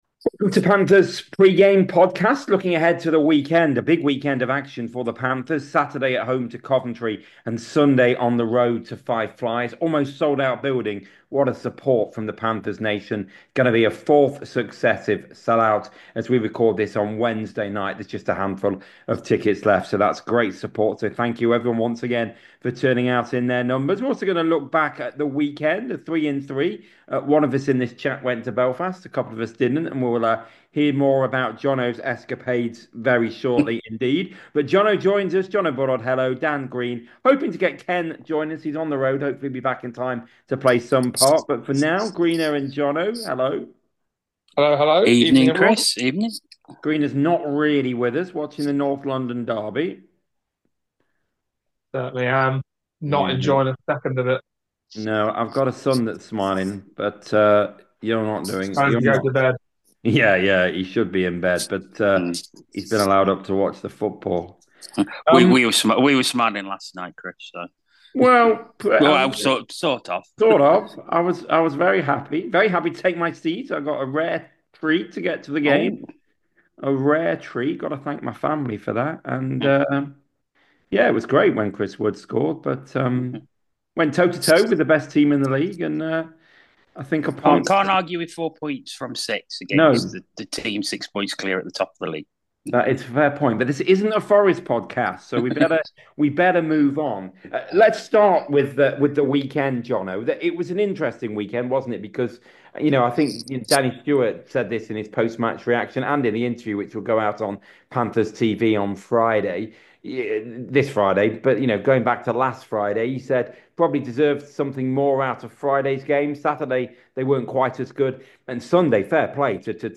chat about a whole host of topics including the demise of fighting in the sport.